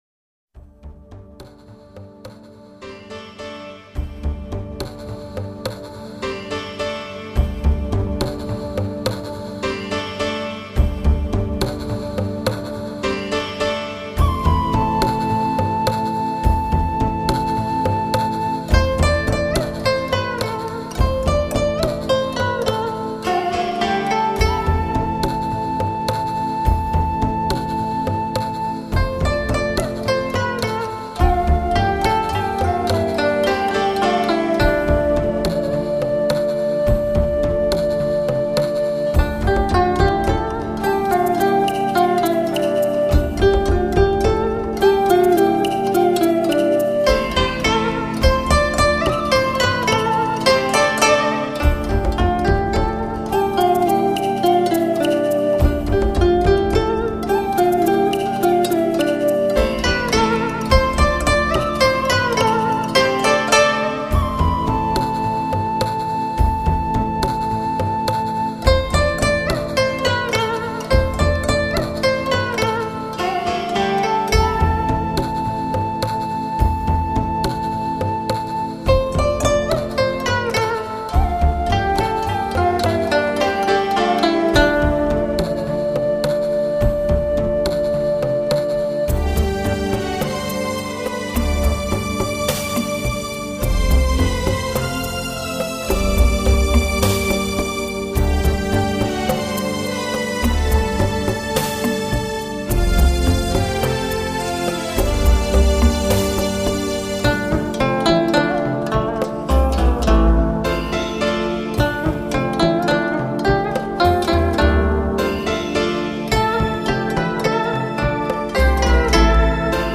这清净微妙之音将把您引向一个崇高圣洁的境界。
医学证实聆听心灵舒压音乐，能有效活化身体机能，